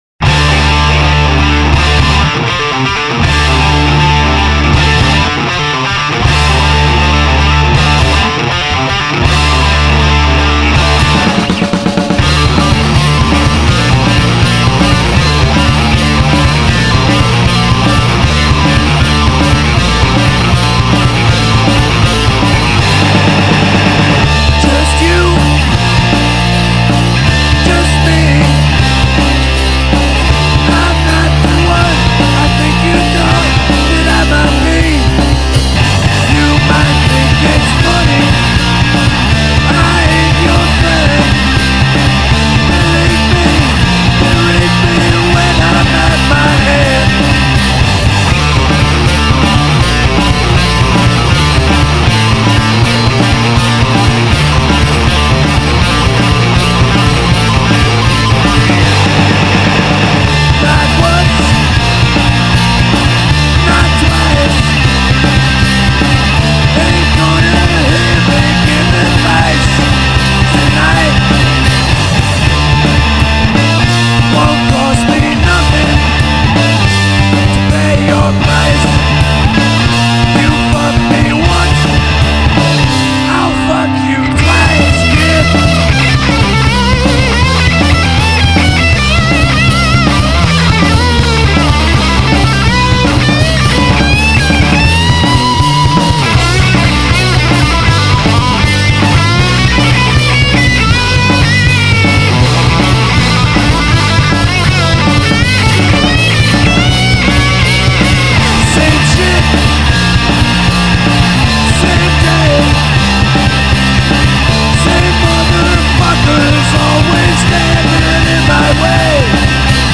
Chicago's Premiere Heavy Metal Rockers